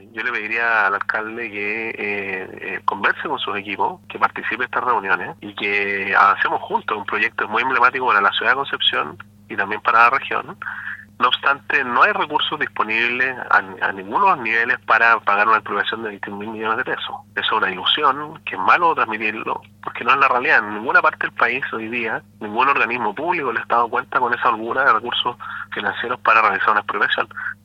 cuna-platita-gobernador.mp3